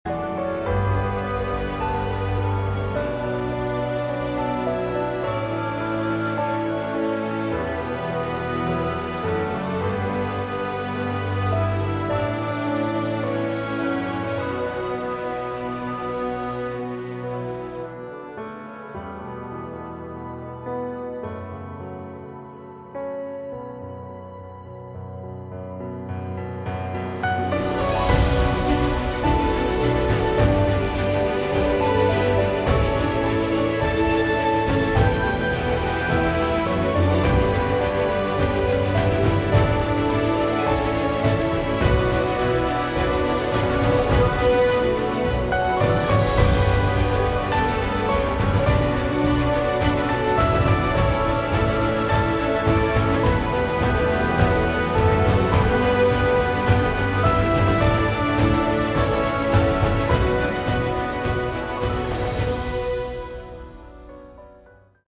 Category: Prog Rock/Metal
vocals
guitars, bass
keyboards
drums